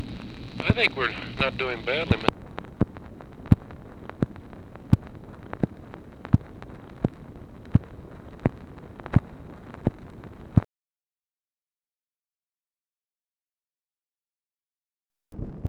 ONLY INITIAL PART OF CONVERSATION RECORDED ON THIS PNO
Conversation with NICHOLAS KATZENBACH, June 30, 1965